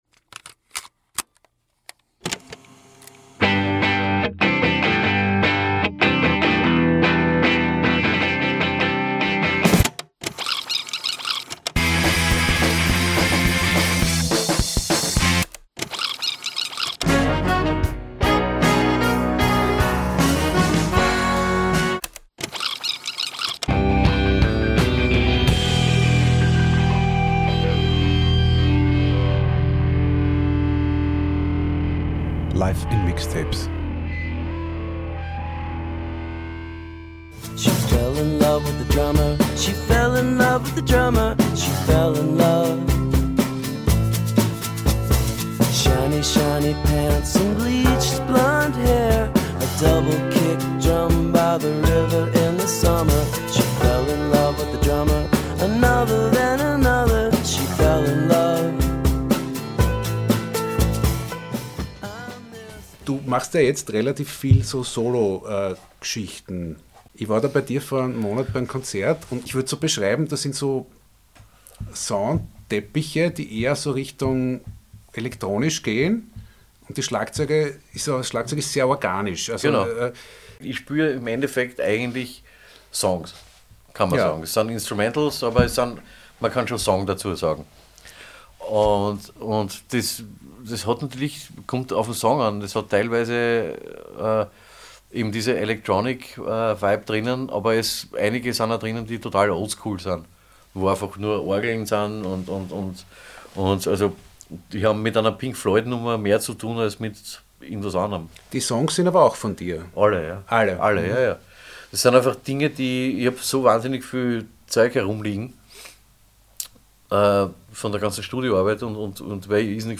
Der heutige zweite Teil unseres Gesprächs mit Christian Eigner dreht sich ganz um seine Arbeit und seine Projekte: Er erzählt uns, wie es ist, eine eineinhalbjährige Welttournee bei Depeche Mode zu spielen und worum es ihm bei seinen Solo-Projekten geht....